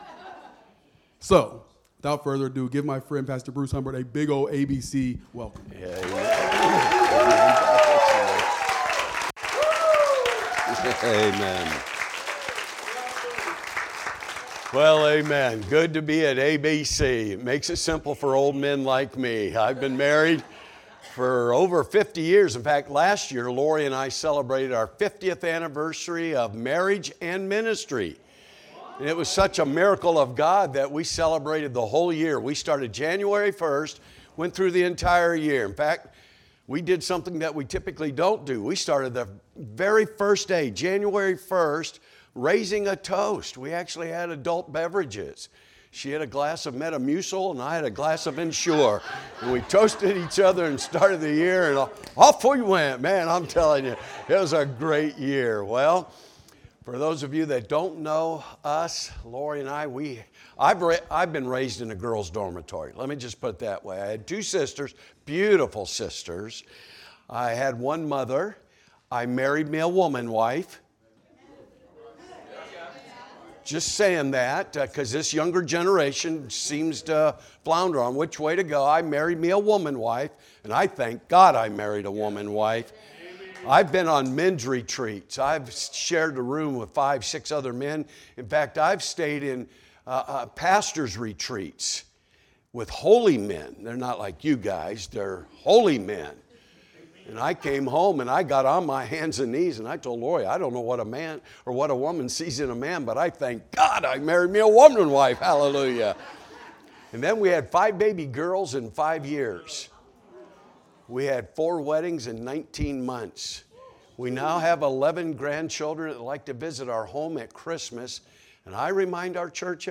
Sunday Morning Worship Revival Service